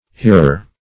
Hearer \Hear"er\ (h[=e]r"[~e]r), n.